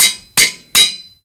anvil_use.ogg